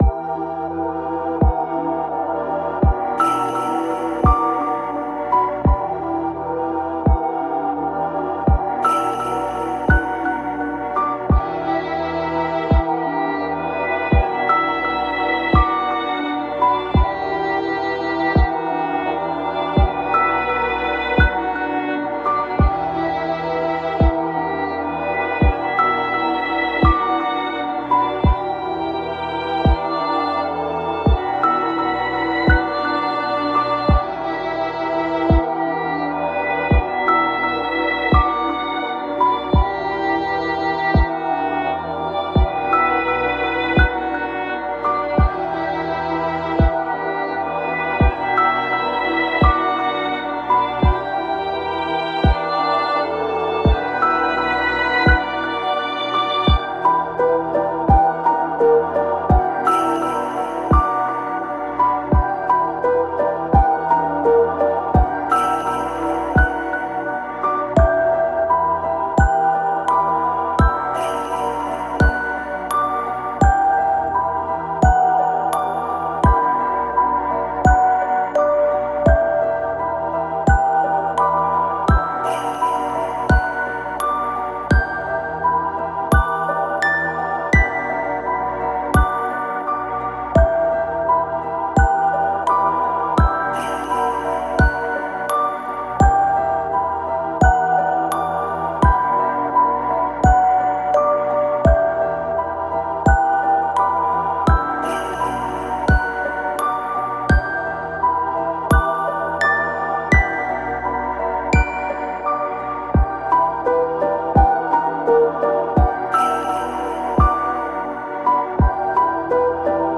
💬誰からも忘れられてしまったもの…そんな悲しいイメージを曲にしました。
少し不気味さもあるかもしれません。